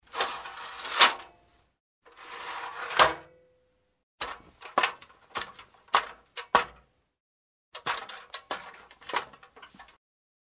Scala, scala a pioli, tintinnio, salita, discesa: